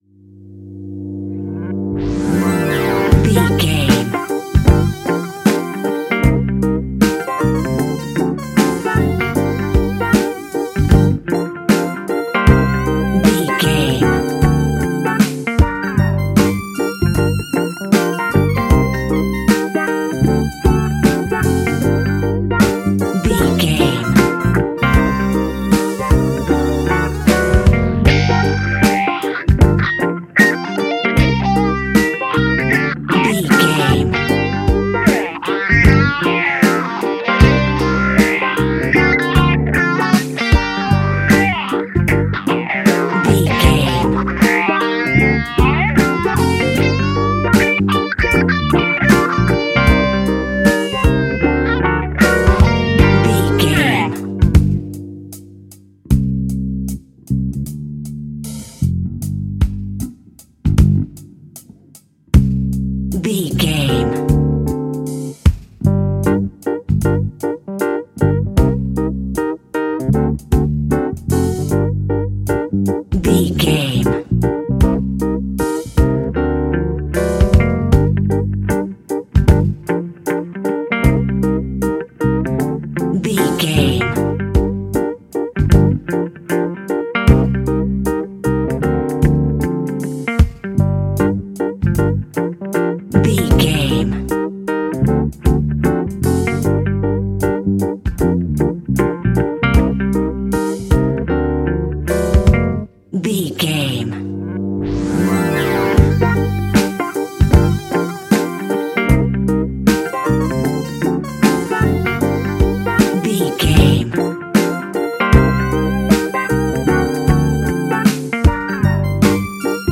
Epic / Action
Fast paced
In-crescendo
Uplifting
Ionian/Major
instrumentals